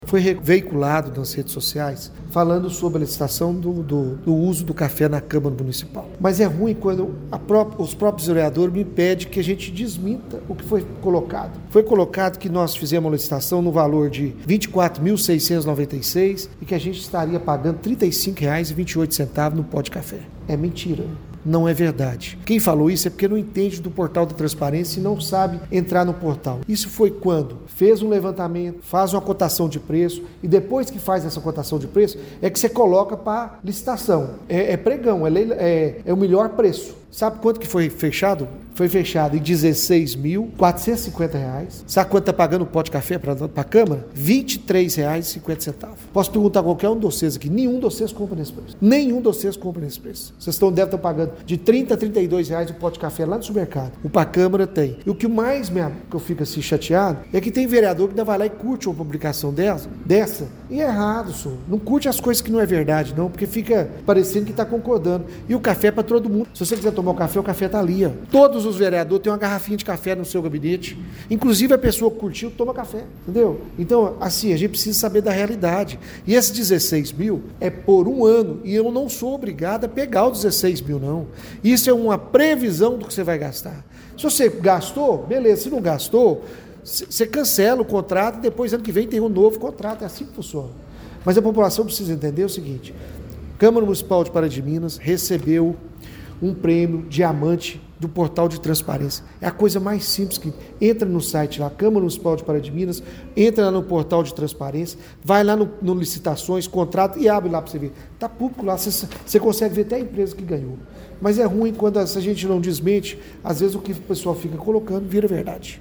Durante entrevista coletiva, o presidente comentou ainda questionamentos sobre a licitação para compra de pó de café. Ele esclareceu que o valor final contratado ficou abaixo do estimado inicialmente e reforçou a transparência do processo: